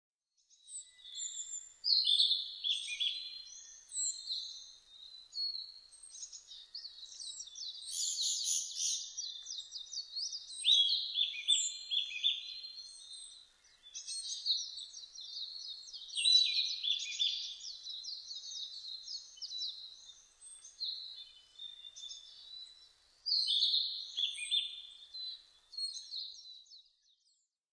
キビタキ　Ficedula narcissinaヒタキ科
日光市稲荷川中流　alt=730m  HiFi --------------
Mic.: Sound Professionals SP-TFB-2  Binaural Souce
他の自然音：　 コゲラ・カラ類・メジロ